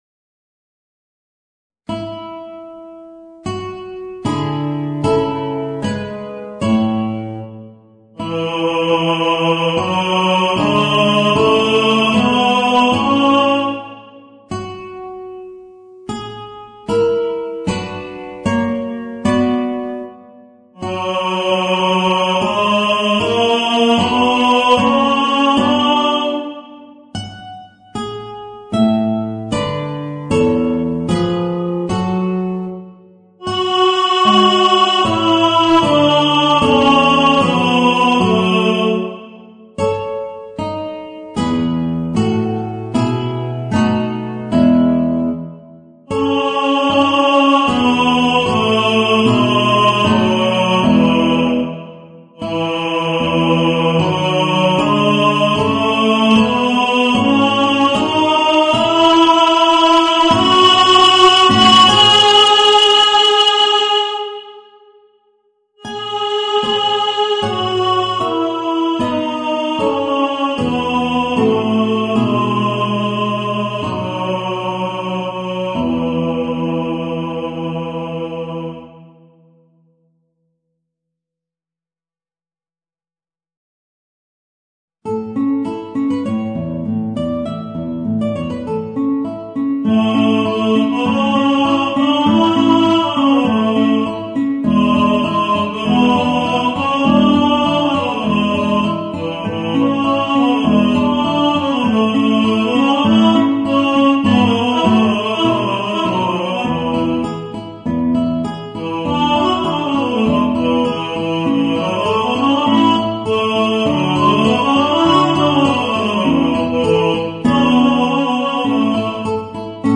Voicing: Guitar and Tenor